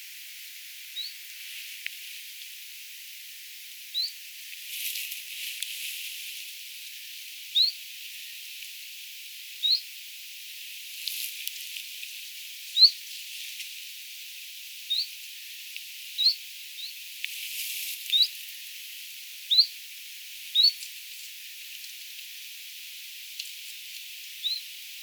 ehkä jotain sellaista kuin bzizt -
Äänite: niitä uudenlaisia tiltaltteja?
läheltä
viela_niita_laheltakuultuja_bizt_tai_vit_tiltaltteja.mp3